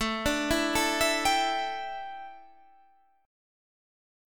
A7sus4 chord